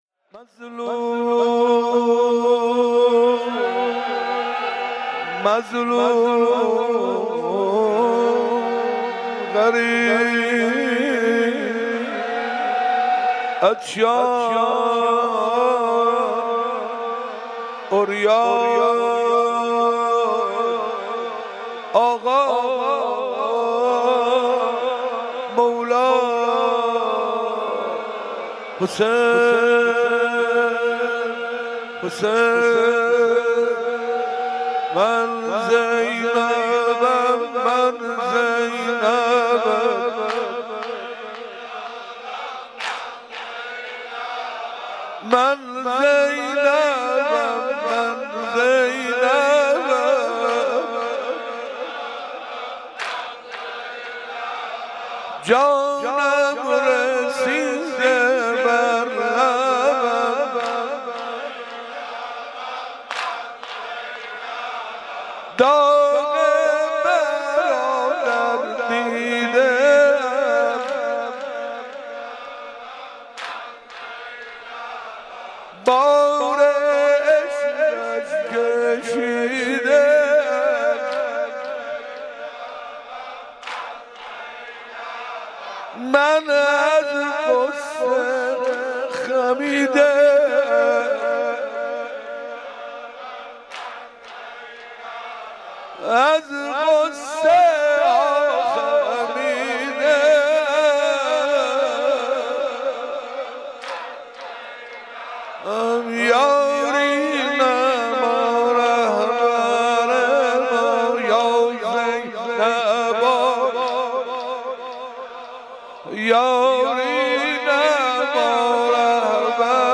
21 محرم 97 - آستان مقدس امامزاده عبدالله - مظلوم